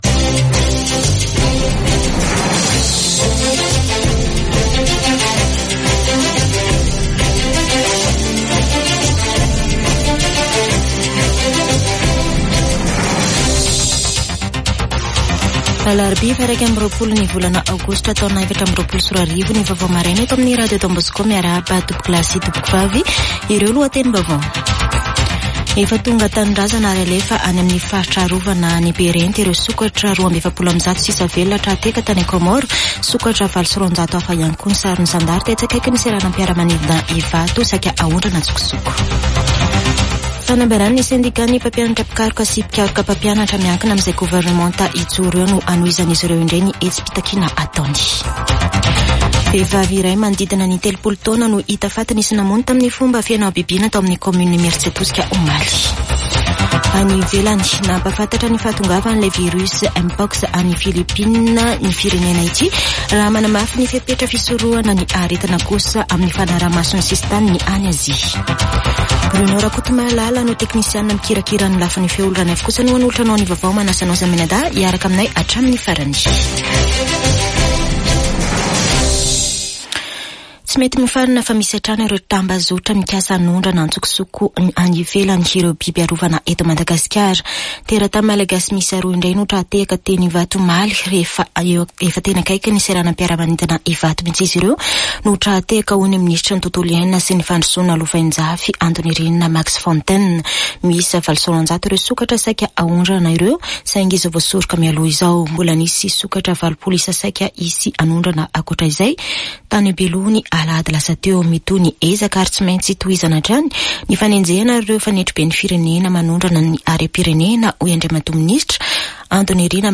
[Vaovao maraina] Alarobia 21 aogositra 2024